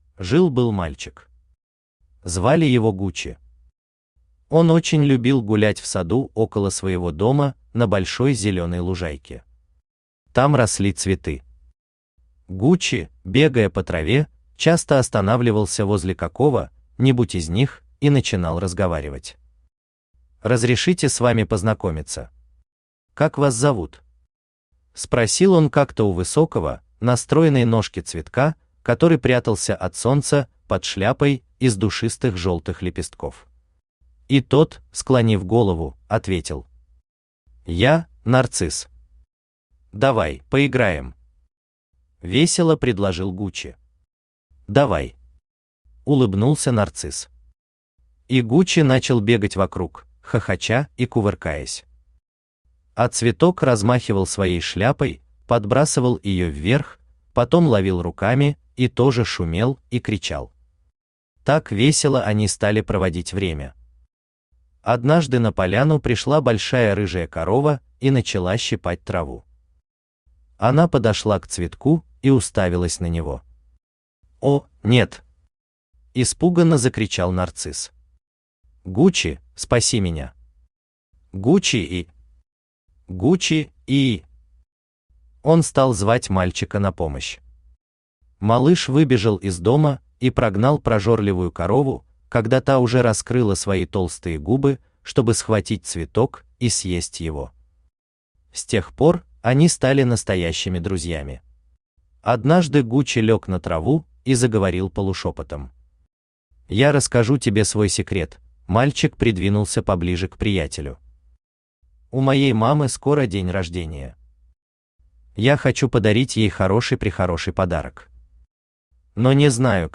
Аудиокнига Приключения Гучи в стране Ароматов | Библиотека аудиокниг
Aудиокнига Приключения Гучи в стране Ароматов Автор Владимир Иванович Чуприна Читает аудиокнигу Авточтец ЛитРес.